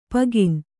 ♪ pagin